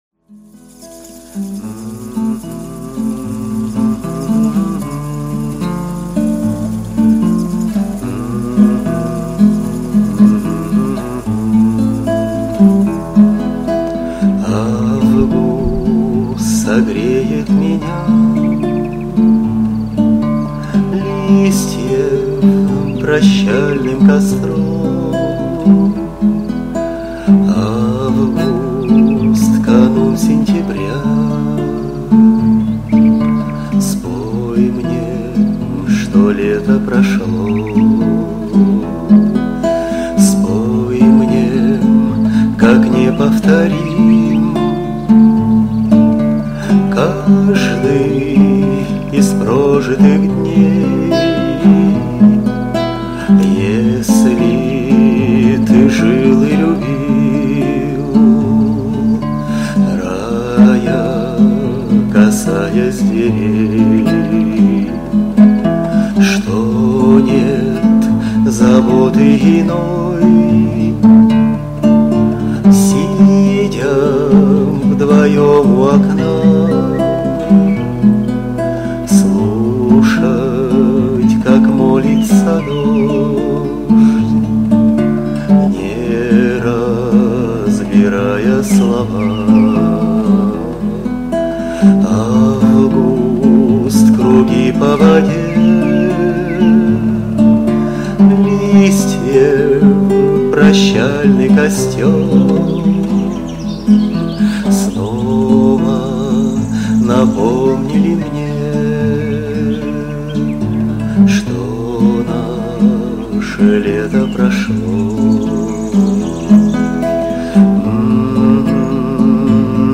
Песня
в исполнении автора